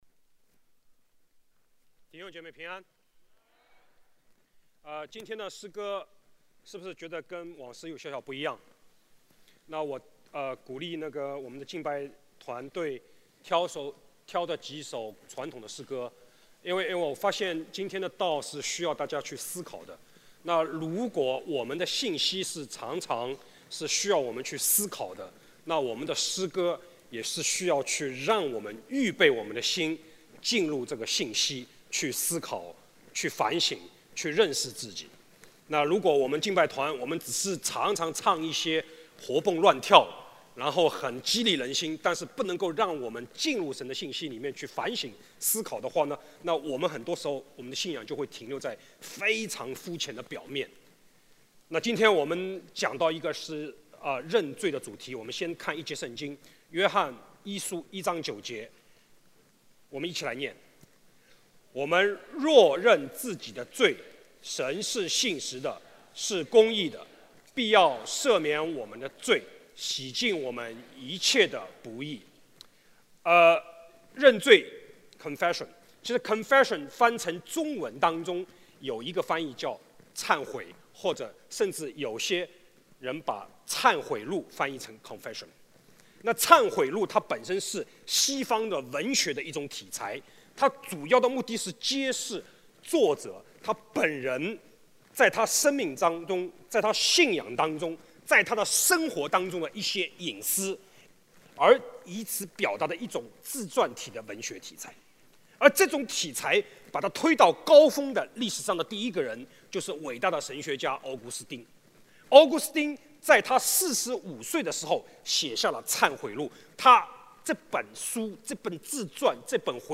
主日证道 | 认罪